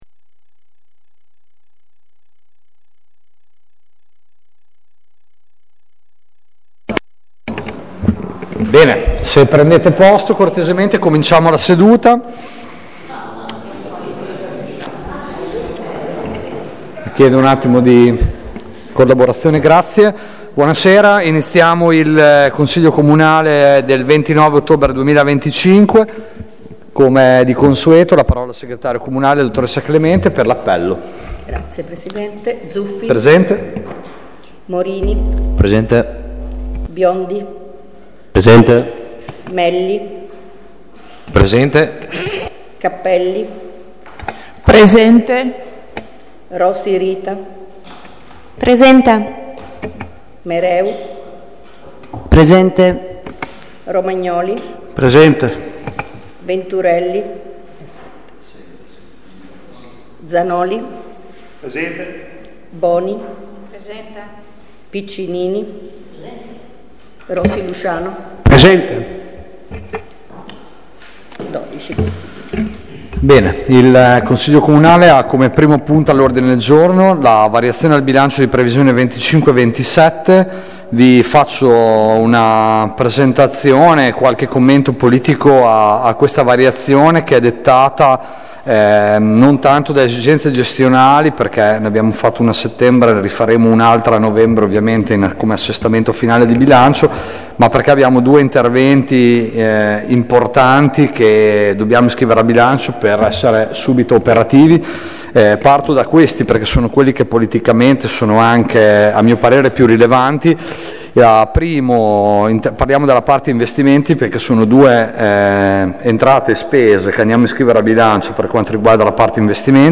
Consiglio Comunale del 29/10/2025